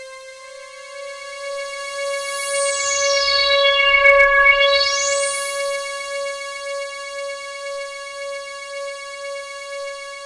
标签： CSharp6 MIDI音符-85 罗兰-JX-3P 合成器 单票据 多重采样
声道立体声